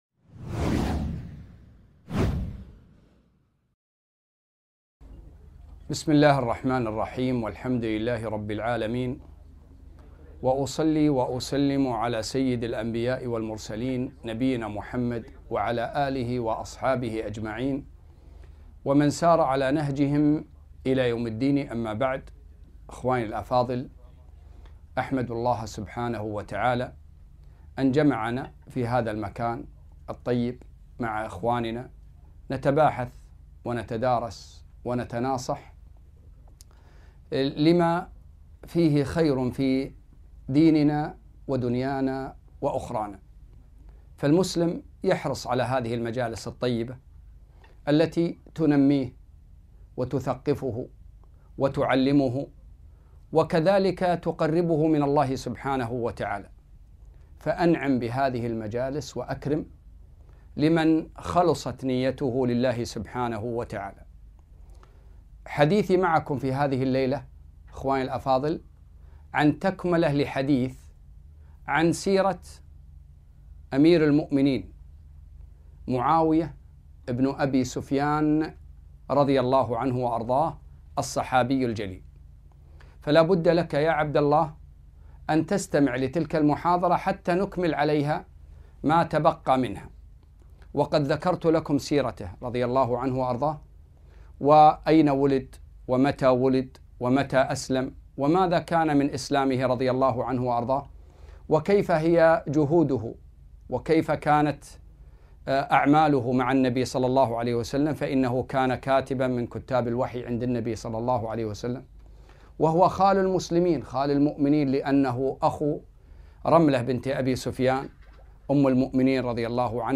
محاضرة - مكانة معاوية رضي الله عنه عند اهل السنه و موقفهم من ابنه يزيد